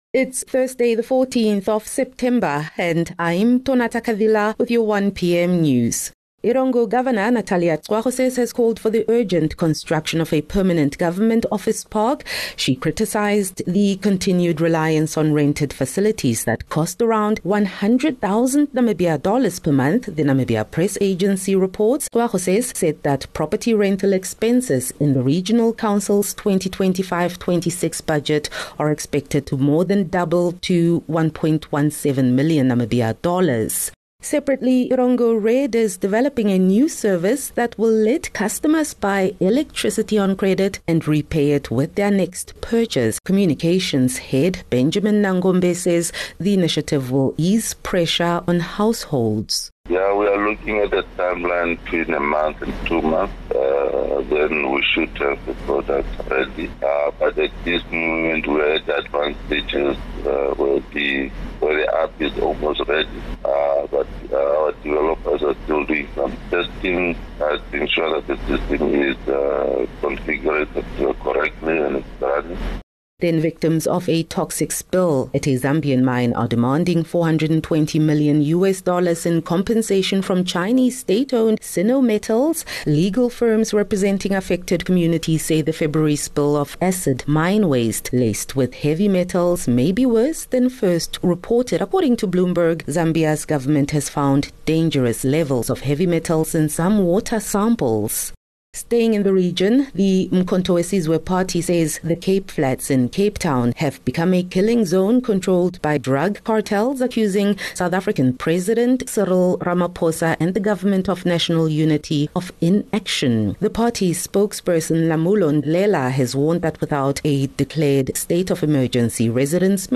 4 Sep 4 September - 1 pm news